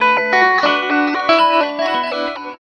69 GTR 3  -R.wav